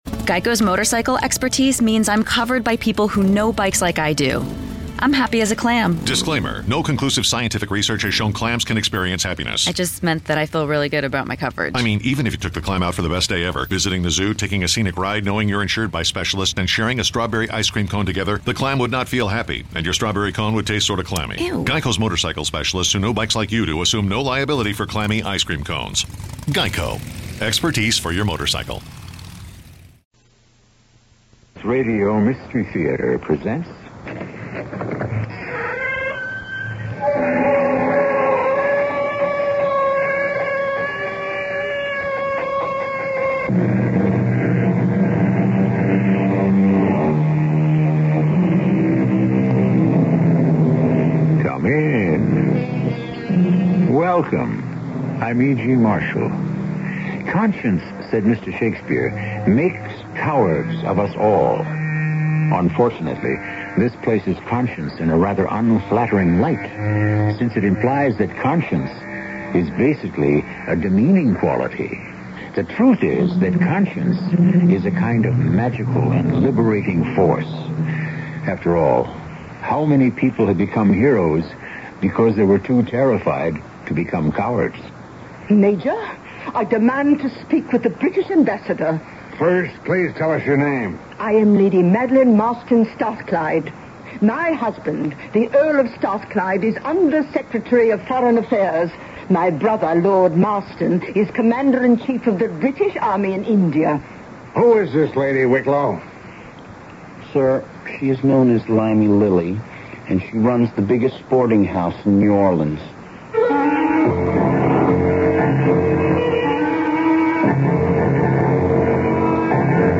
CBS Radio Mystery Theater (a.k.a. Radio Mystery Theater and Mystery Theater, sometimes abbreviated as CBSRMT) was a radio drama series created by Himan Brown that was broadcast on CBS Radio Network affiliates from 1974 to 1982